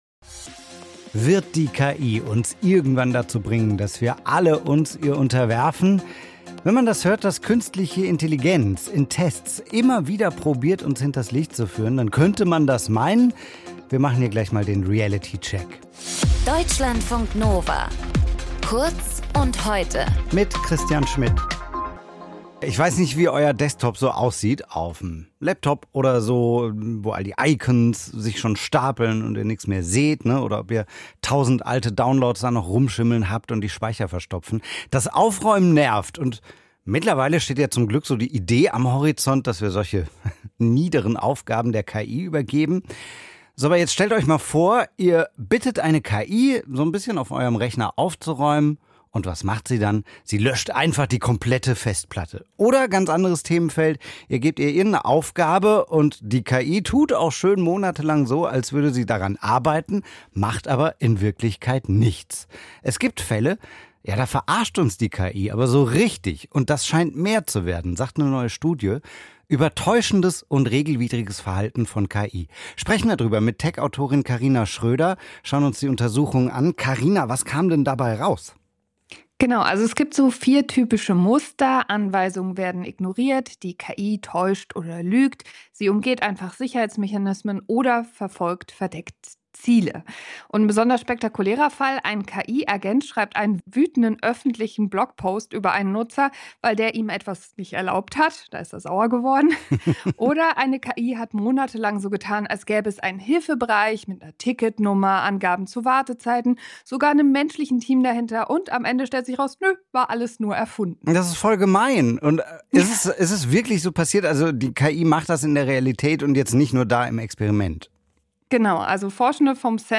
Tech-Talk - KI-Modelle verhalten sich immer betrügerischer